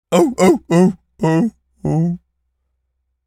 seal_walrus_death_slow_02.wav